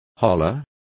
Complete with pronunciation of the translation of hollering.